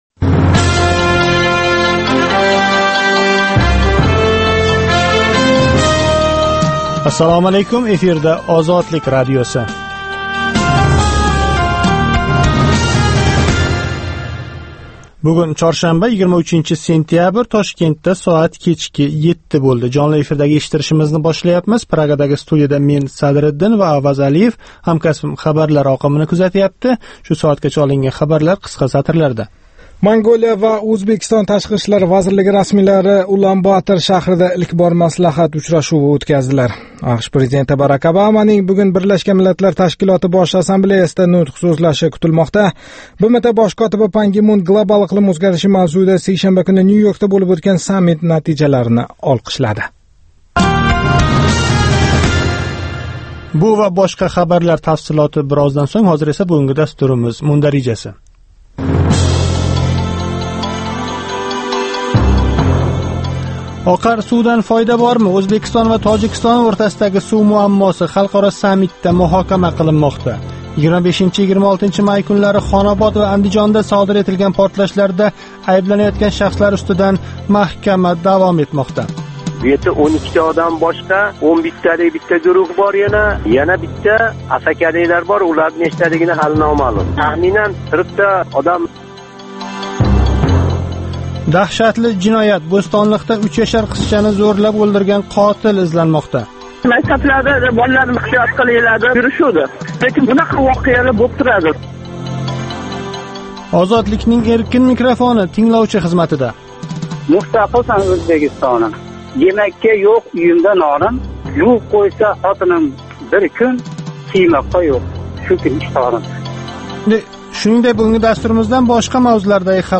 Озодлик радиосининг ўзбек тилидаги кечки жонли дастурида куннинг энг муҳим воқеаларига оид сўнгги янгиликлар¸ Ўзбекистон ва ўзбекистонликлар ҳаëтига доир лавҳалар¸ Марказий Осиë ва халқаро майдонда кечаëтган долзарб жараëнларга доир тафсилот ва таҳлиллар билан таниша оласиз.